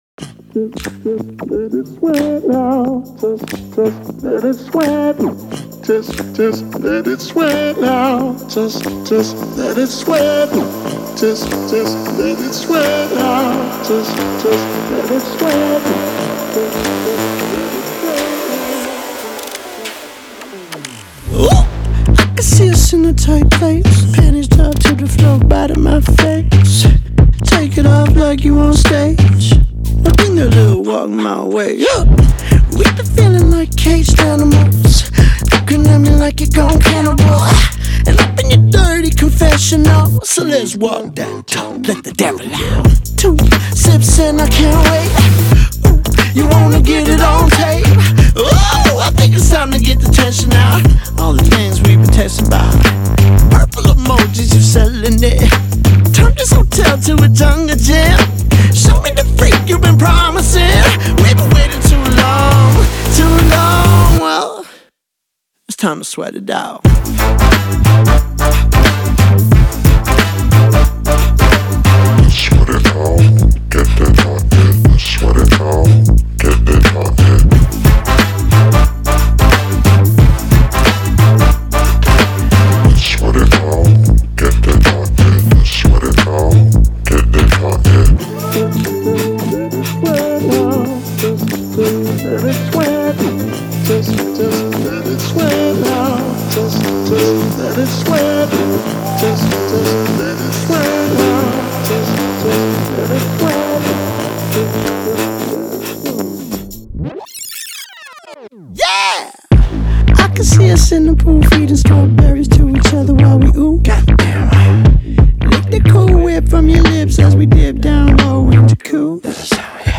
это зажигательный трек в жанре поп и EDM